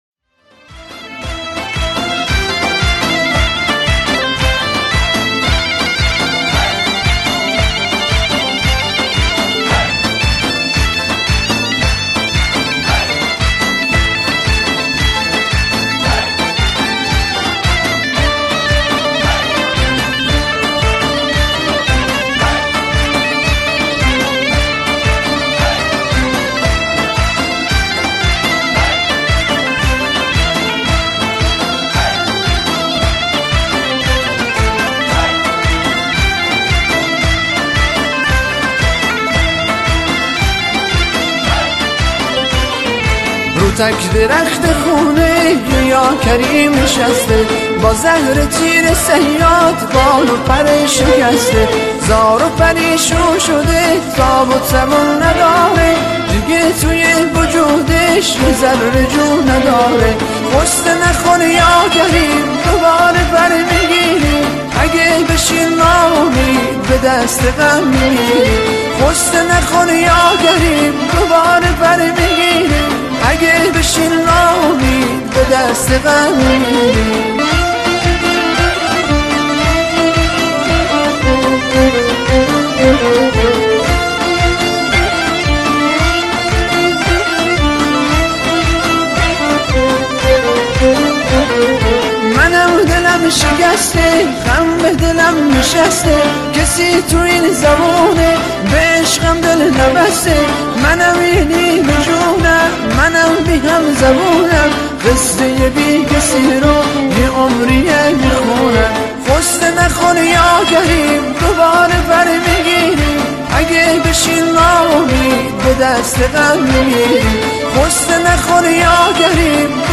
یک آهنگ کردی زیبا